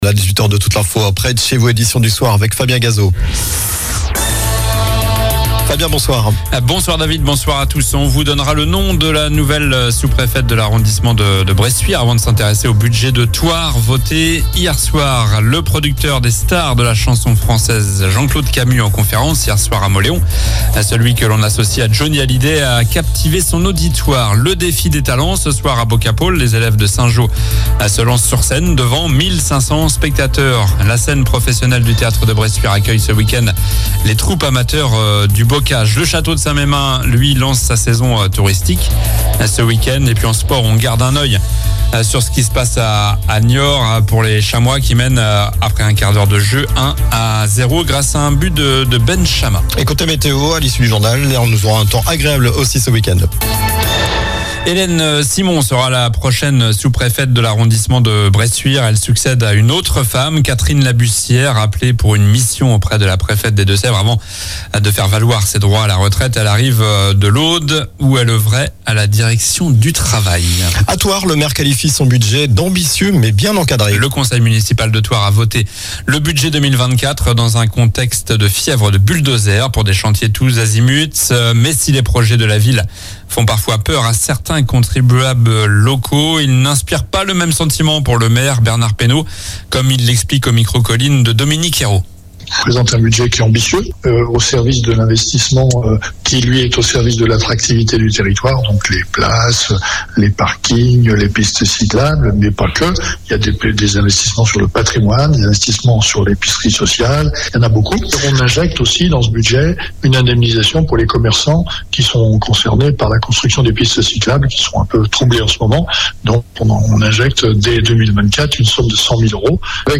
Journal du vendredi 12 avril (soir)